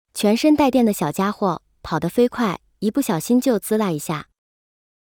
thunder.mp3